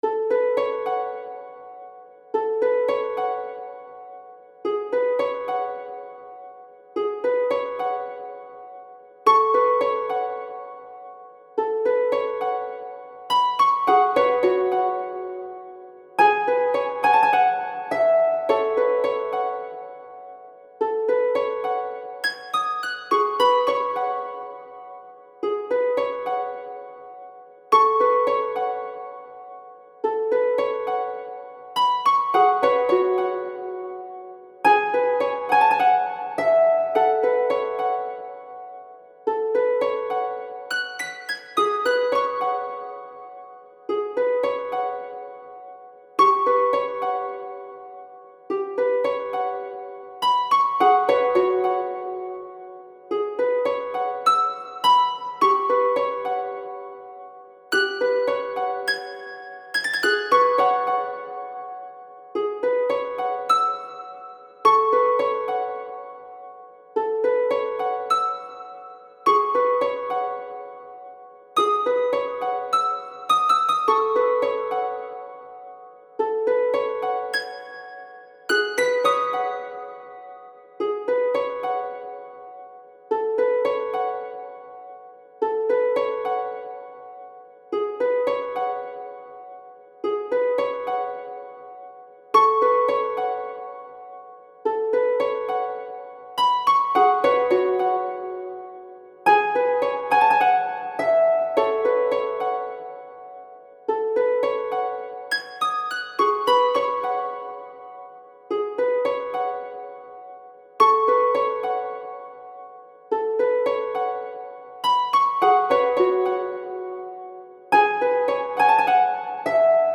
不思議系のハープの曲です